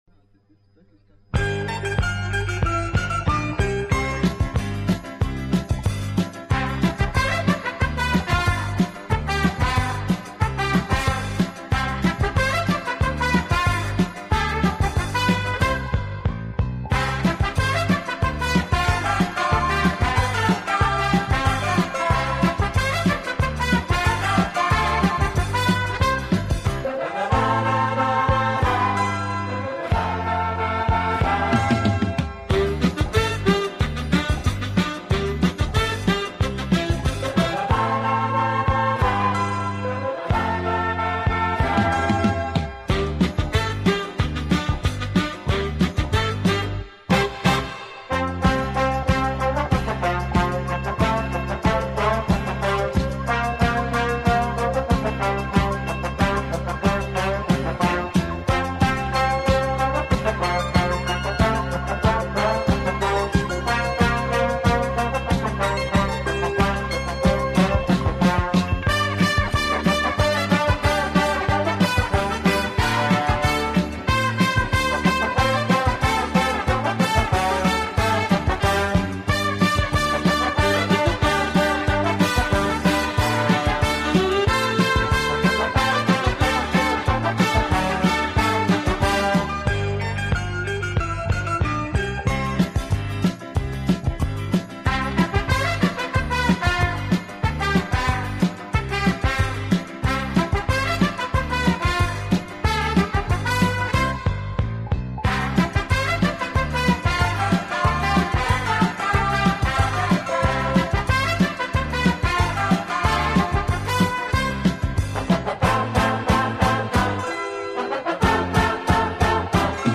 Genre:Pop
Polka Hits with a Big Band Sound??
So, get ready for polka enjoyment in today’s modern sound !!